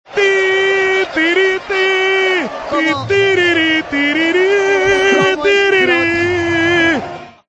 pasodoble
retransmitiendo al Gran Canaria en la Copa del Rey de baloncesto